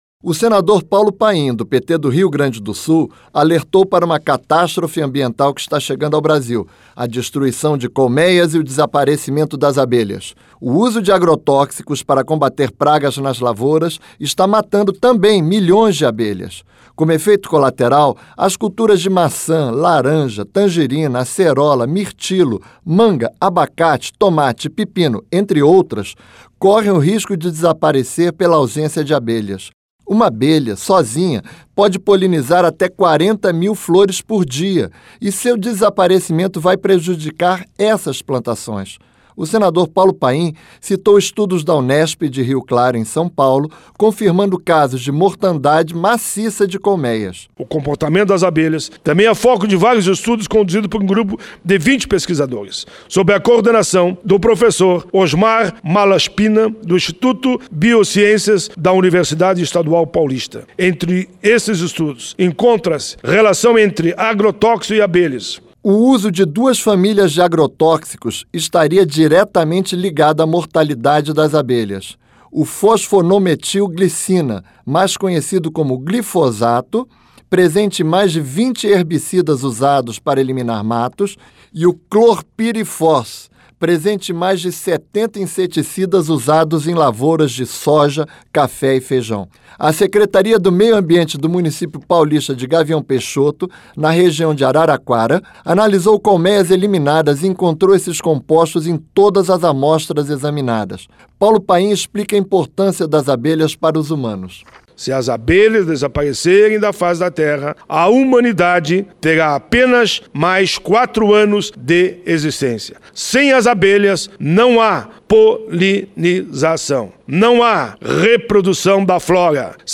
LOC: O SENADOR PAULO PAIM, DO PT DO RIO GRANDE DO SUL, ESTÁ PREOCUPADO COM A MORTANDADE DE ABELHAS POR CAUSA DO USO DE AGROTÓXICOS.